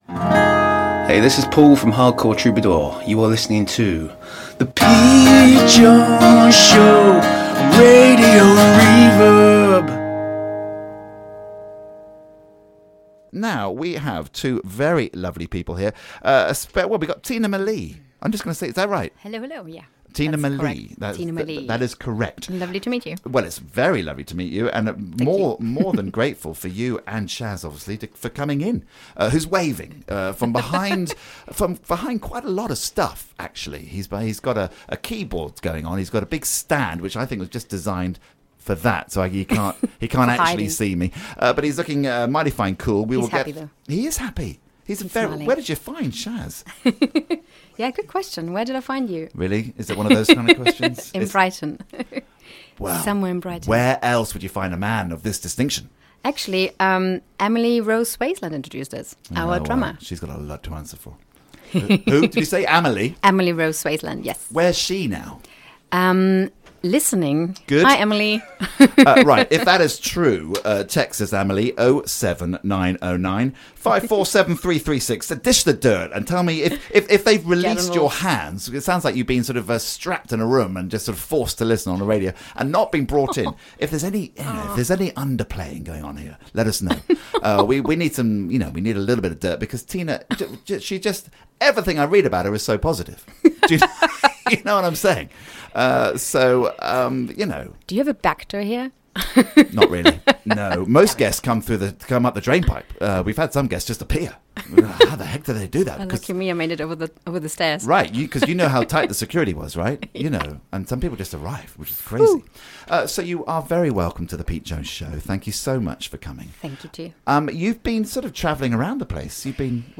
Chat and live session